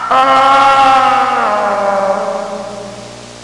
Falling Sound Effect
Download a high-quality falling sound effect.
falling-2.mp3